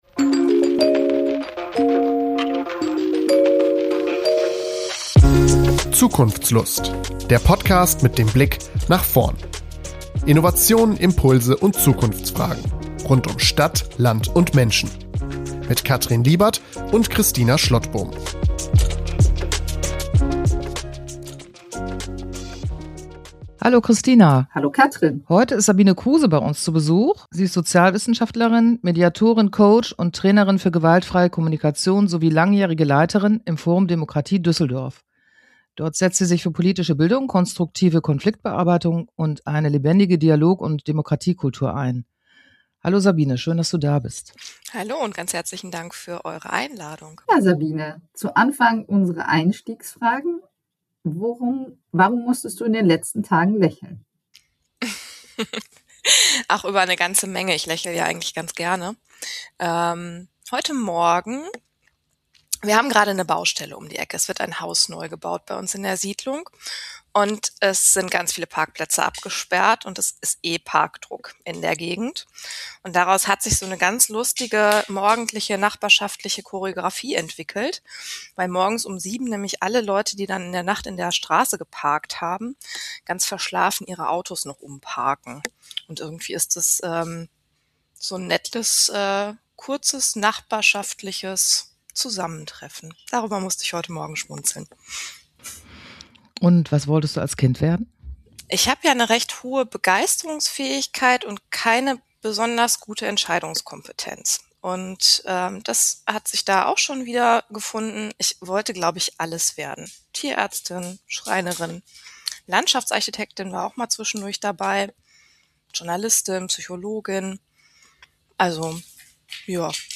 Ein Gespräch über Haltung, Verständigung und lebendige Demokratie.